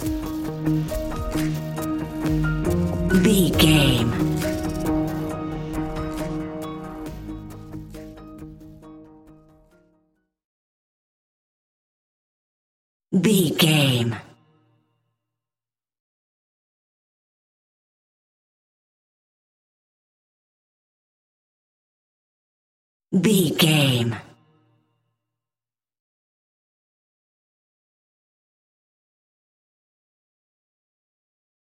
Ionian/Major
D♯
electronic
techno
trance
synths
synthwave